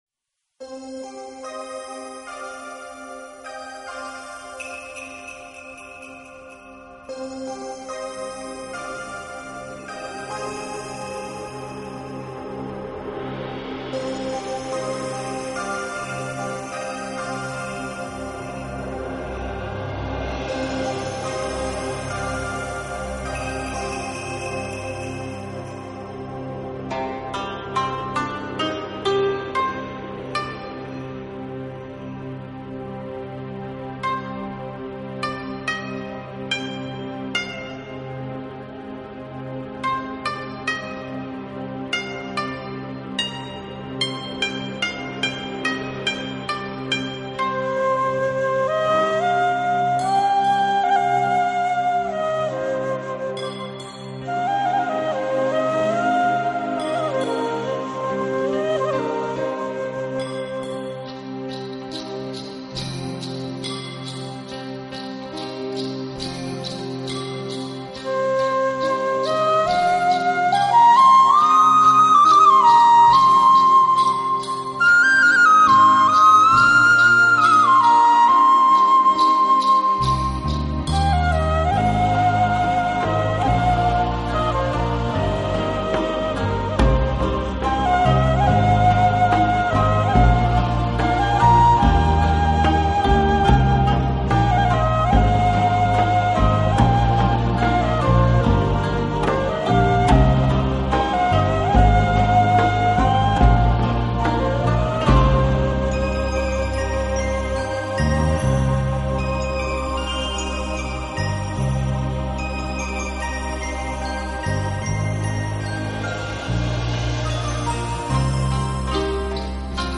【笛子专辑】
旋律清新、明快，令闻
旋律很轻快，音乐听起来非常流畅。